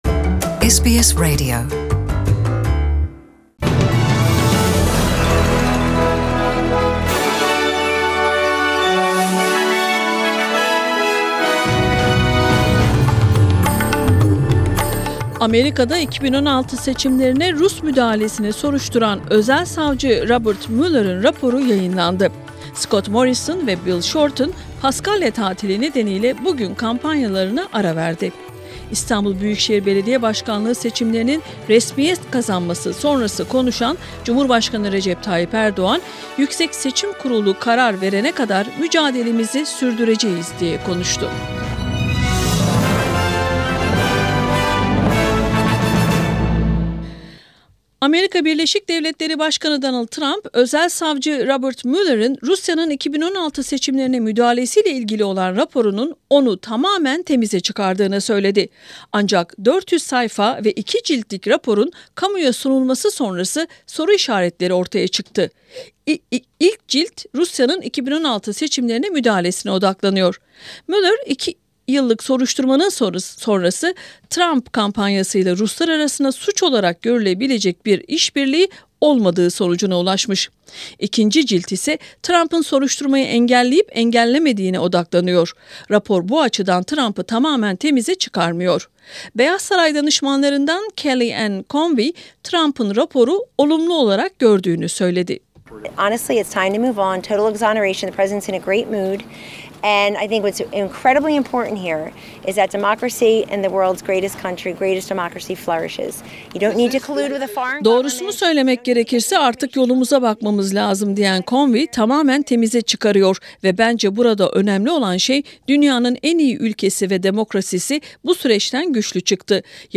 News on April 19, 2019, Friday by SBS Turkish